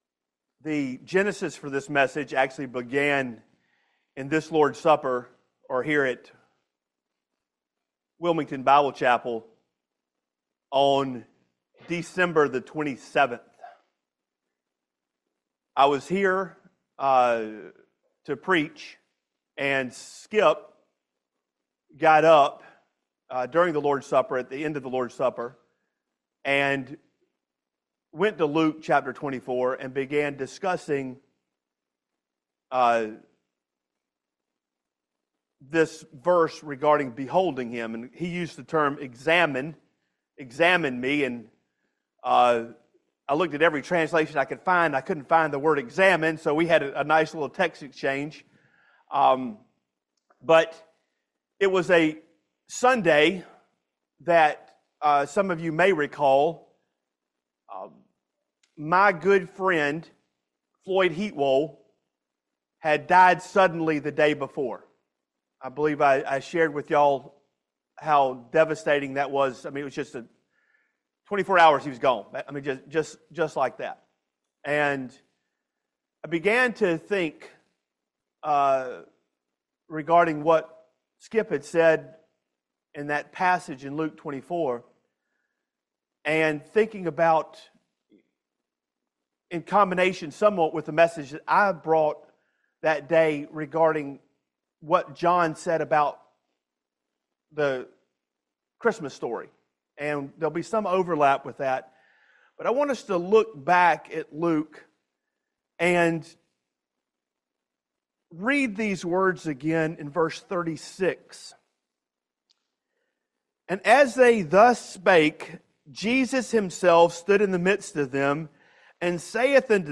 Luke 24:36-39 Service Type: Family Bible Hour Six truths about the person of Christ.